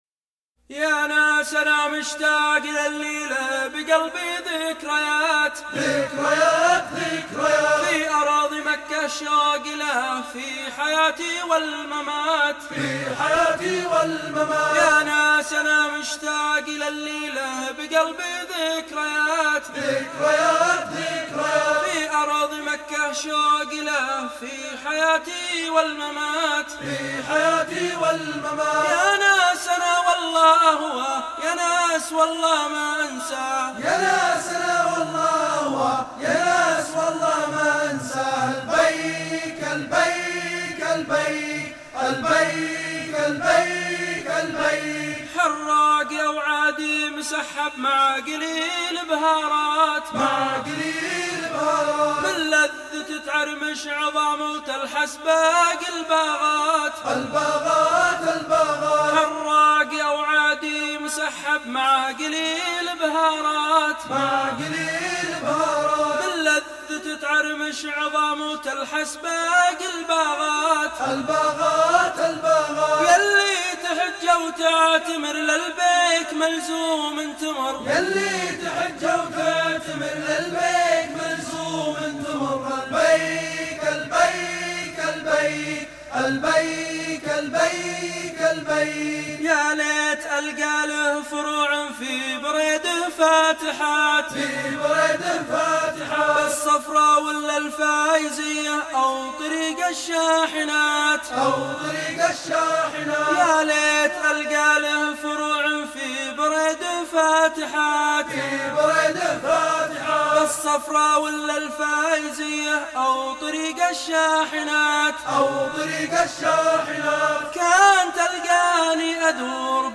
اما الانشودة بصراحه تضحك رحمت اللي ينشد من اعماقي احسه من قلب